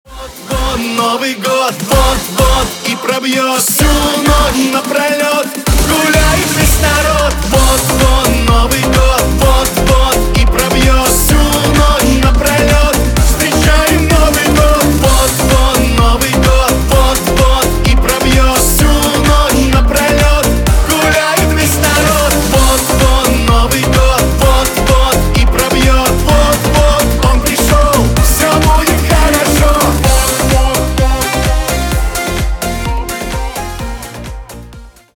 Поп Музыка
весёлые # новогодние